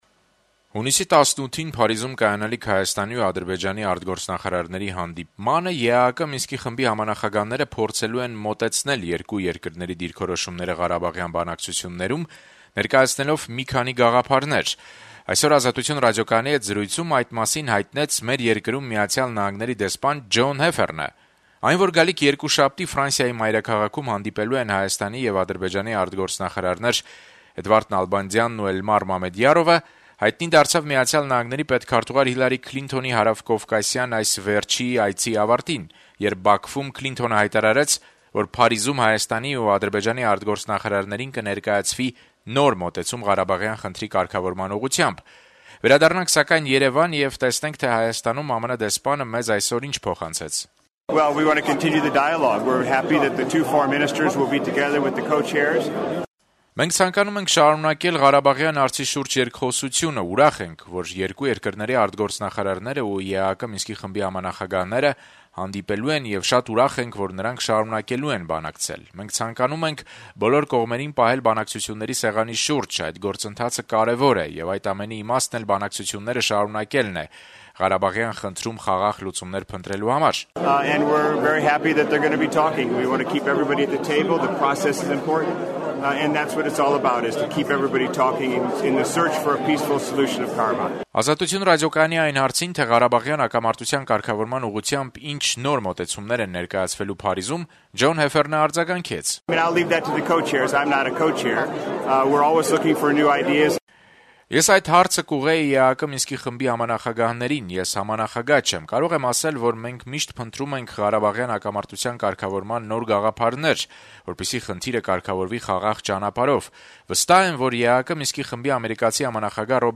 Այդ մասին «Ազատություն» ռադիոկայանի հետ զրույցում հայտնեց Հայաստանում ԱՄՆ-ի դեսպան Ջոն Հեֆերնը: